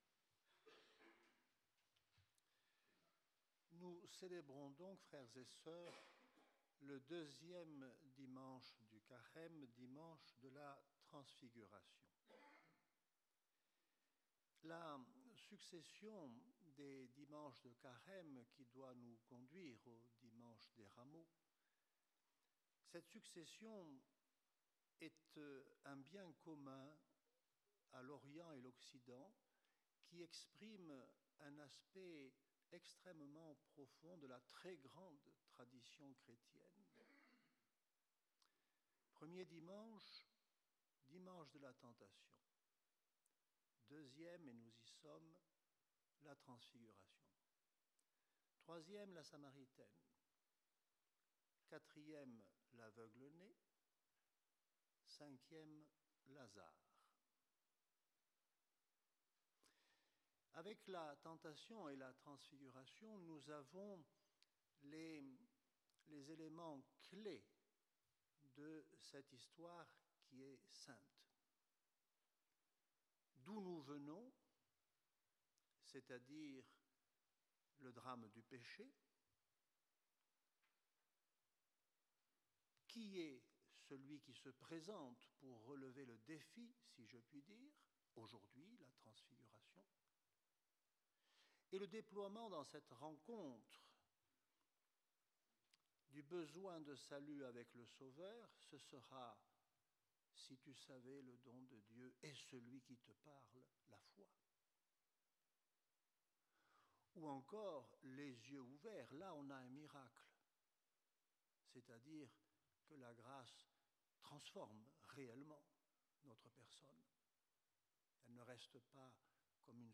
Nous regrettons la qualité de cet enregistrement. L'homélie peut néanmoins être entendue si vous utilisez des écouteurs et que vous montez le volume à fond.